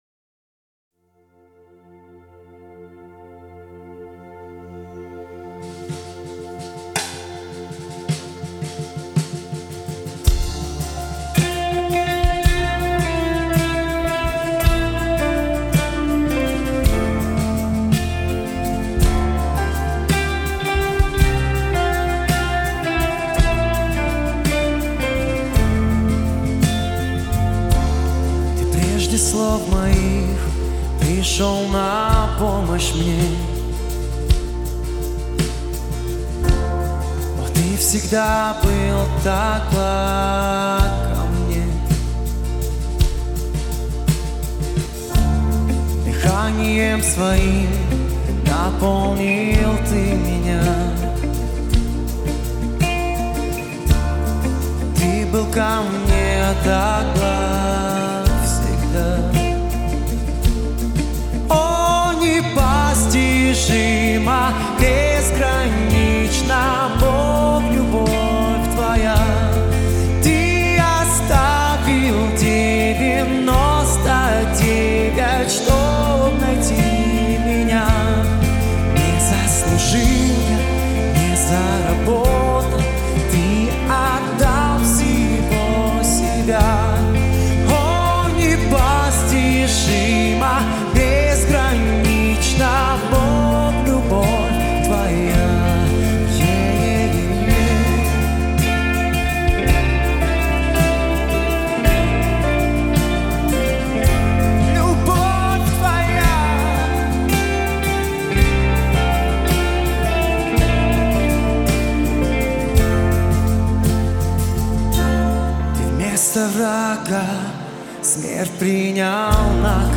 567 просмотров 465 прослушиваний 38 скачиваний BPM: 166